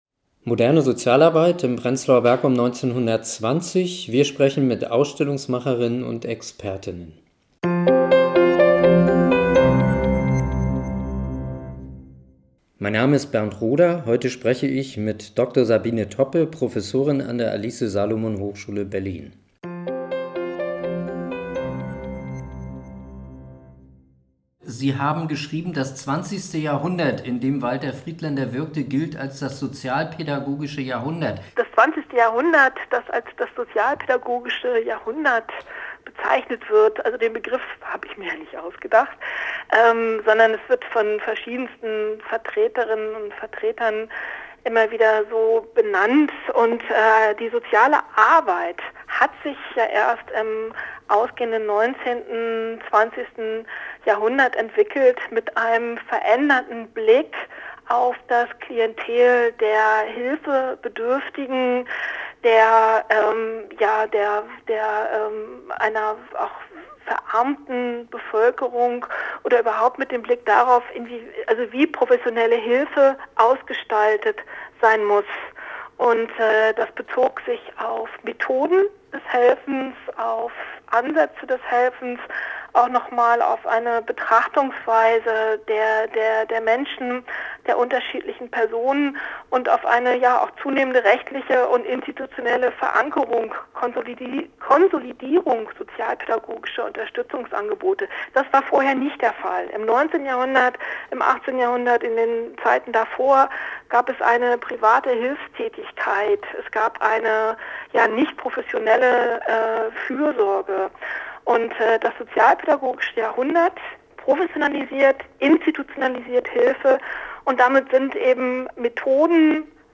Interviews zum Thema: Aufbruch und Reformen – Pionierinnen und Pioniere der modernen Sozialarbeit in Prenzlauer Berg während der Weimarer Republik | Teil 2
Moderne Sozialarbeit in Prenzlauer Berg um 1920 – wir sprechen mit Ausstellungsmacher_innen und Expertinnen und Experten!